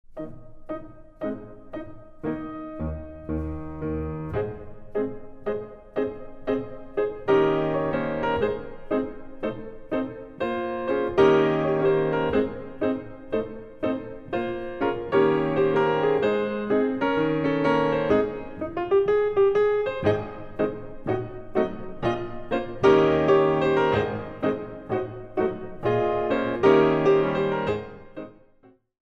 Compositions for Ballet Class
The CD is beautifully recorded on a Steinway piano.